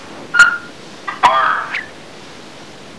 However, he also knows a couple of phrases: (click to hear Wolfgang speak)